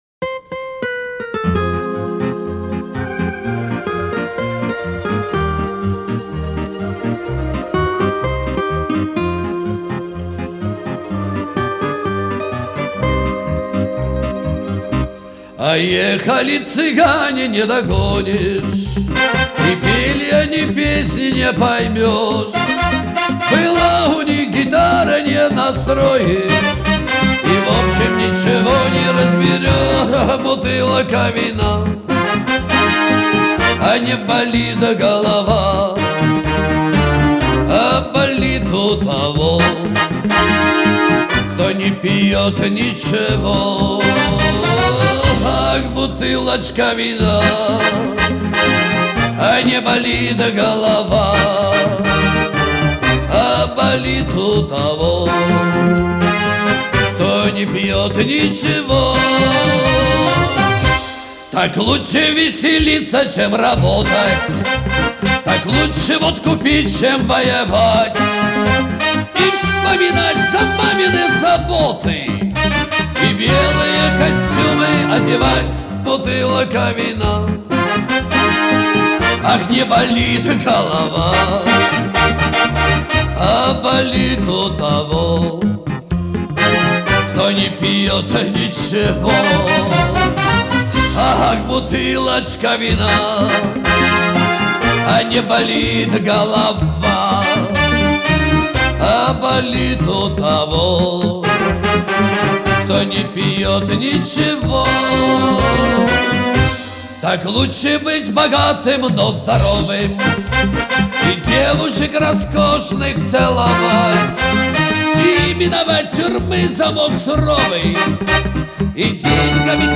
Жанр: Шансон